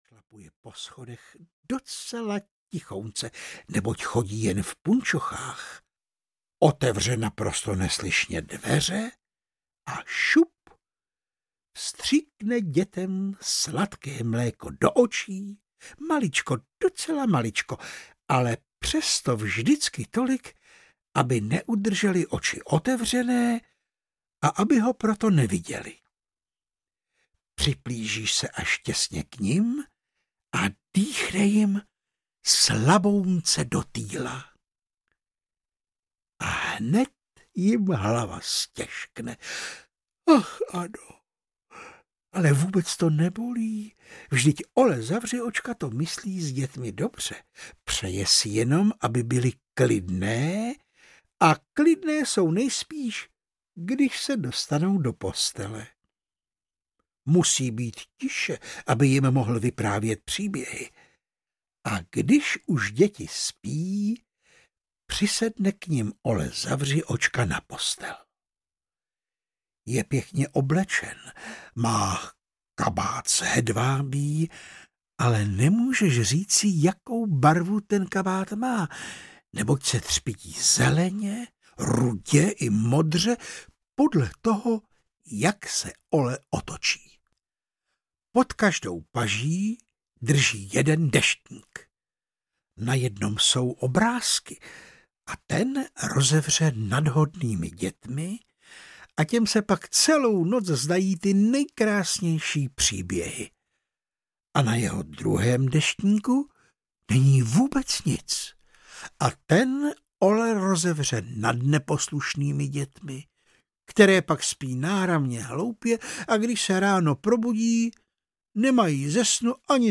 Ole Zavřiočka audiokniha
Ukázka z knihy
• InterpretVáclav Knop